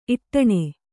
♪ iṭṭaṇe